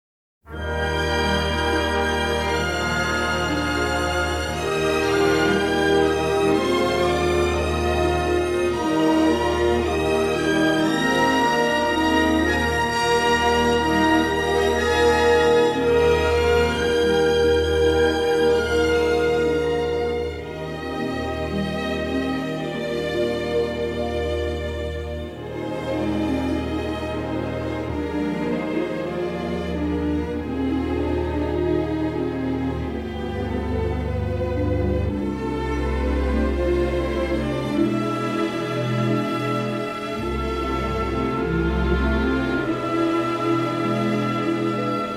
and he responded with a gorgeous, symphonic score.
stereo LP configurations